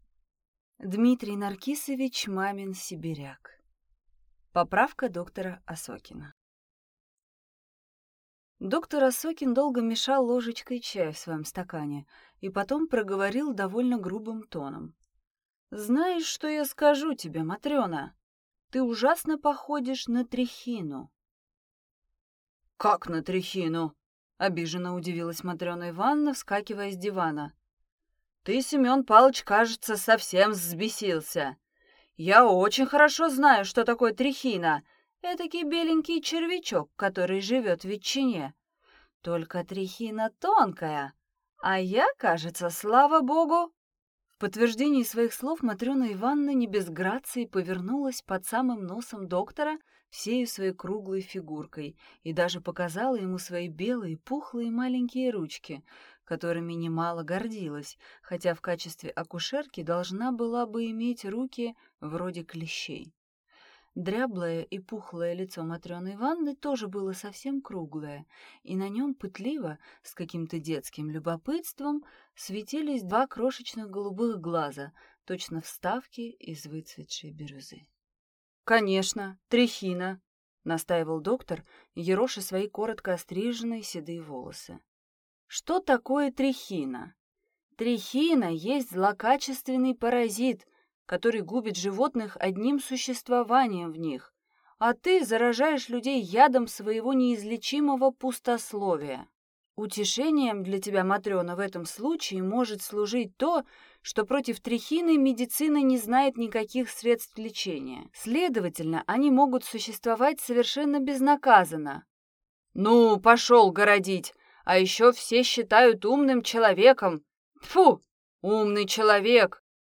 Аудиокнига Поправка доктора Осокина | Библиотека аудиокниг